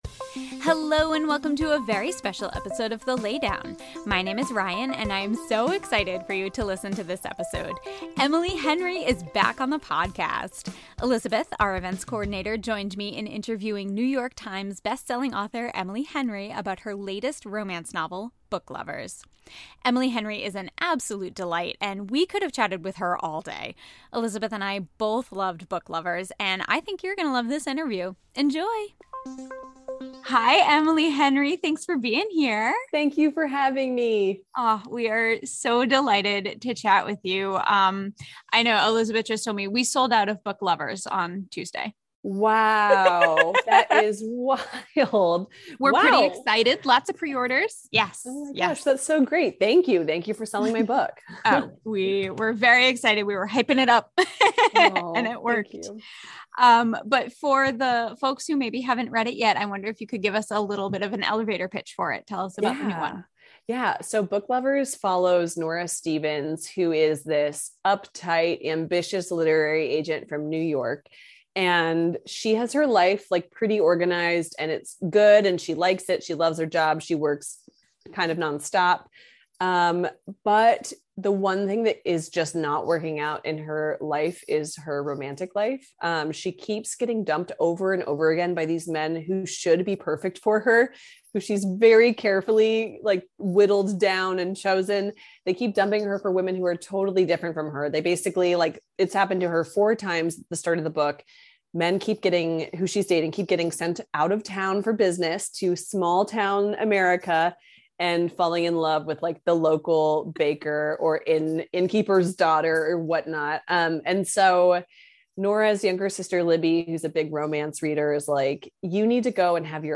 Emily Henry Interview for Book Lovers!